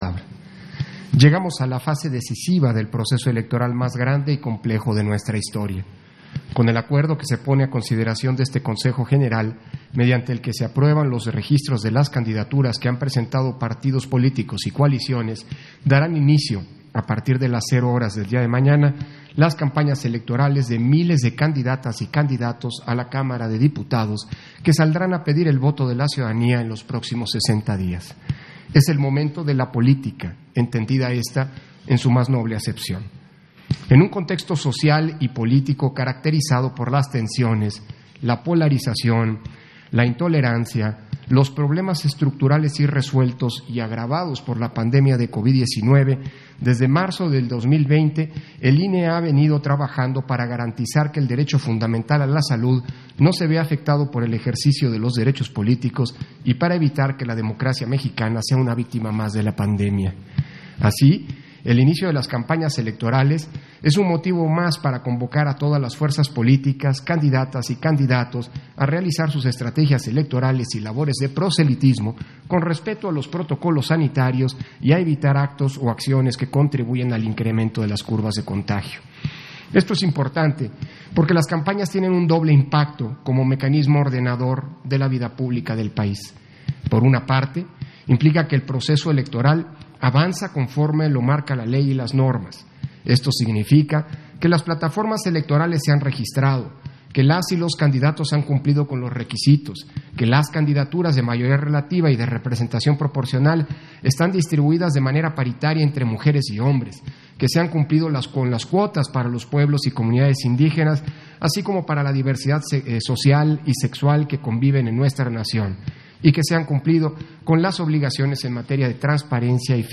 030421_AUDIO_INTERVENCIÓN-CONSEJERO-PDTE.-CÓRDOVA-PUNTO-ÚNICO-SESIÓN-ESPECIAL - Central Electoral